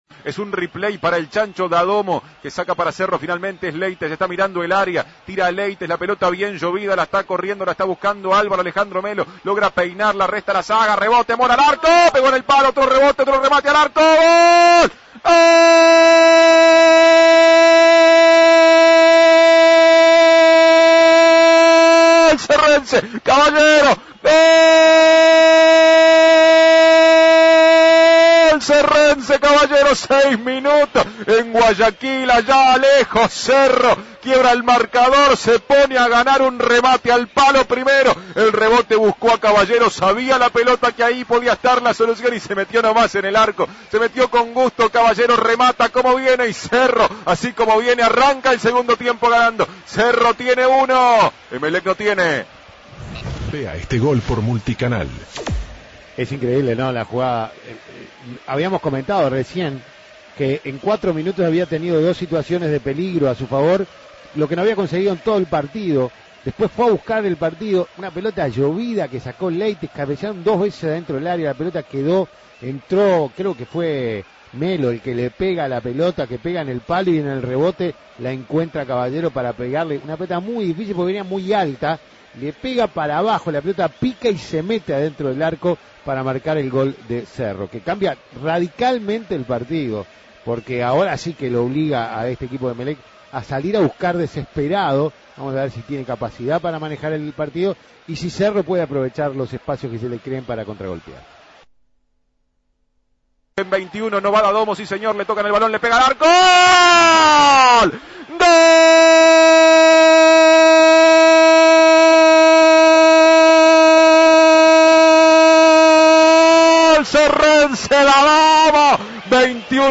Goles y comentarios ESCUCHE LOS GOLES DEL TRIUNFO DE CERRO SOBRE EMELEC Imprimir A- A A+ Cerro logró una victoria histórica como visitante sobre Emelec 2-1.